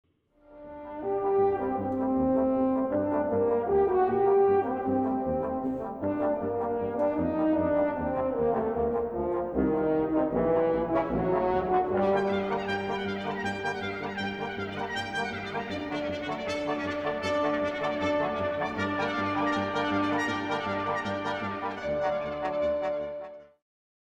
slag_snaar_blaasinstrument4.mp3